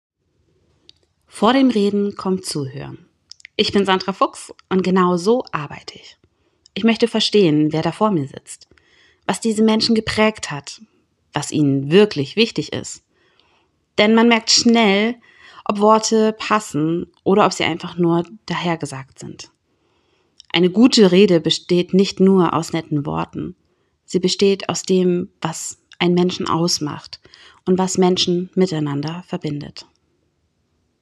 So klingt meine Stimme